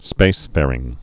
(spāsfârĭng)